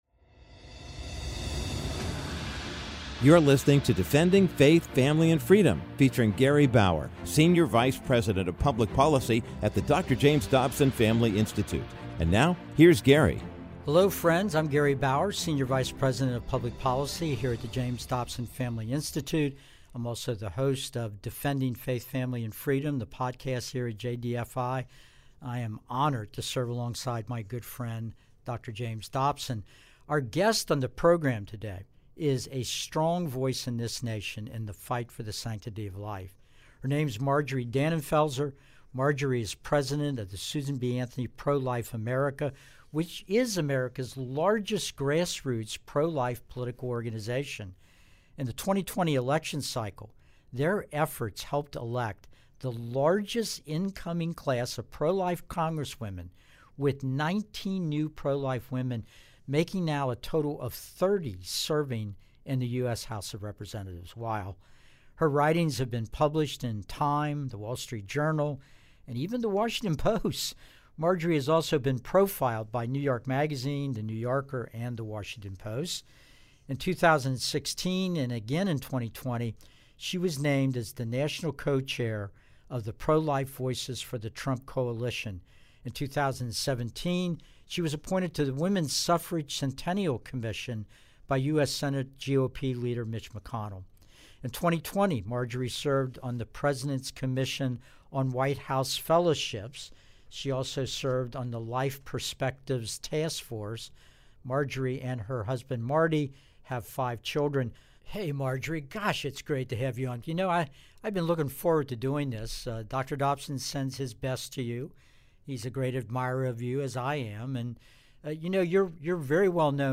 In this week's episode of Defending Faith, Family, and Freedom, Gary Bauer, senior vice president of public policy at the James Dobson Family Institute, interviews special guest Marjorie Dannenfelser, president of Susan B. Anthony Pro-Life America. Dannenfelser defines the next chapter in the pro-life/pro-personhood movement as "good versus evil."